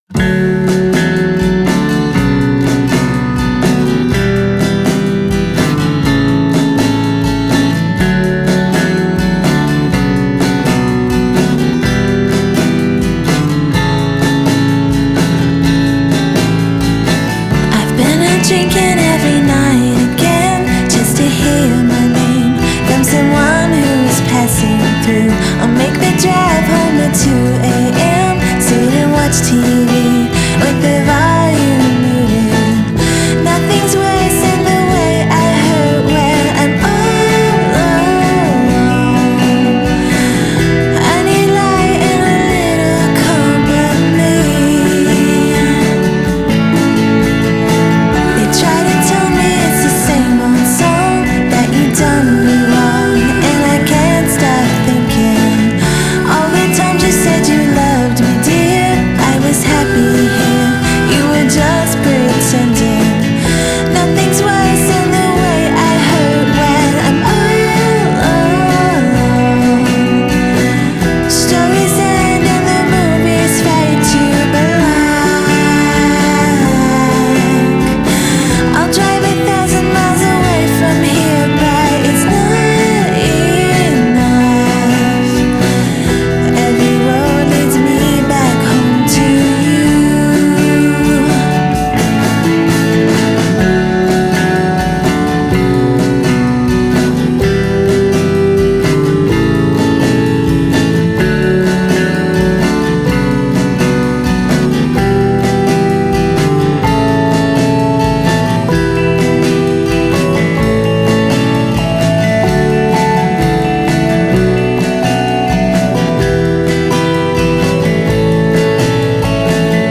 vocals, acoustic guitar
drums
bass
viola, percussion, vocals
rhodes piano
Genre: Indie Pop / Twee / Female Vocal